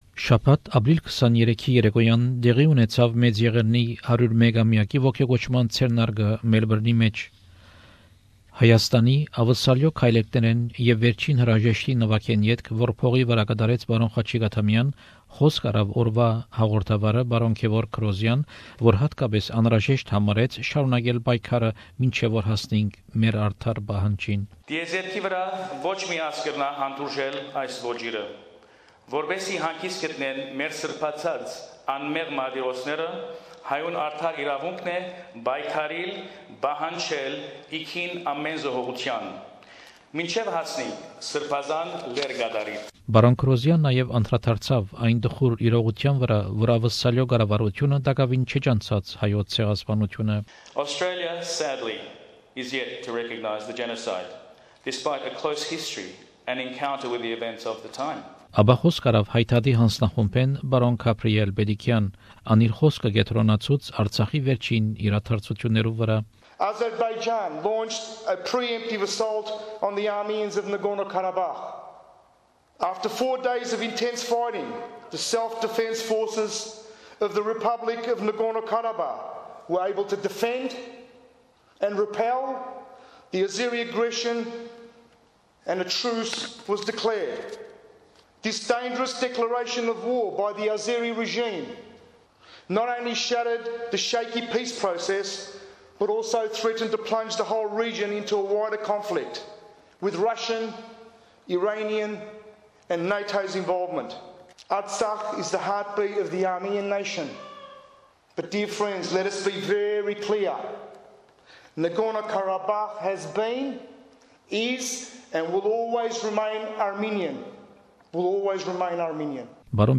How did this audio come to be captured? We Remember And Demand, 101st Anniversary of the Armenian Genocide Commemoration event in Melbourne. The Turkish government denies it committed genocide.